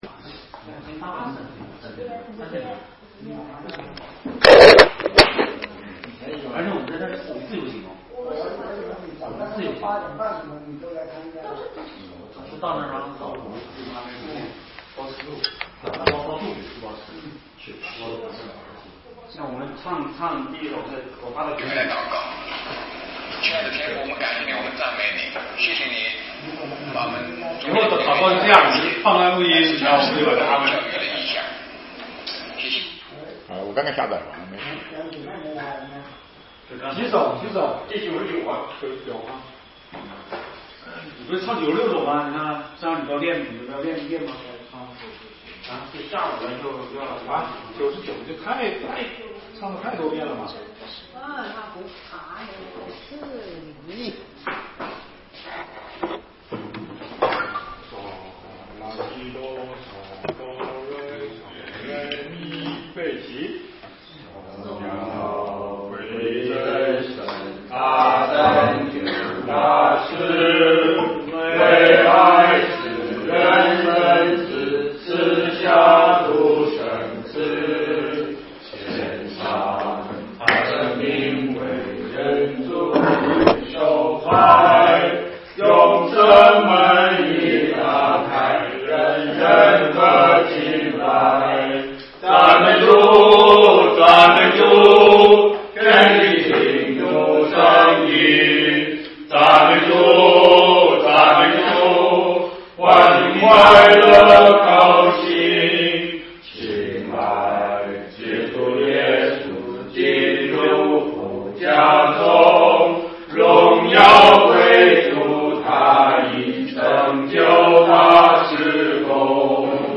出埃及记第4讲 2020年12月1日 下午8:53 作者：admin 分类： 出埃及记小组查经 阅读(4.68K